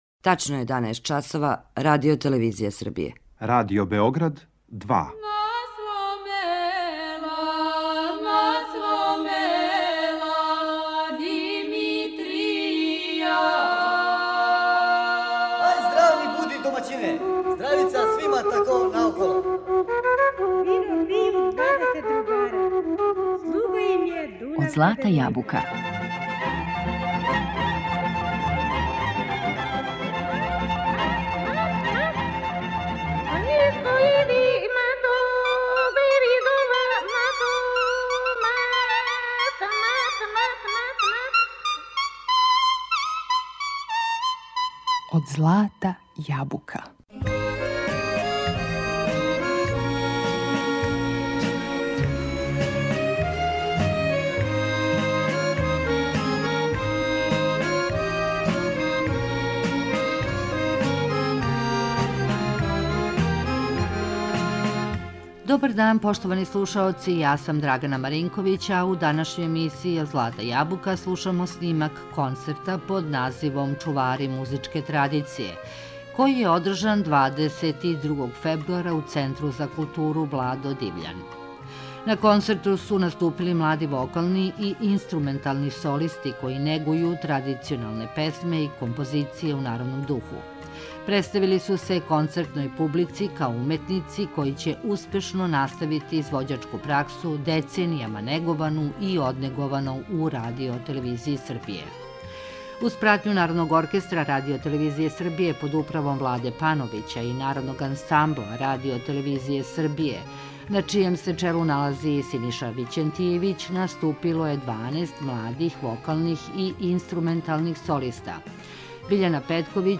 Снимак концерта „Чувари традиције"
На концерту су наступили млади вокални и инструментални солисти који негују традиционалне песме и композиције у народном духу.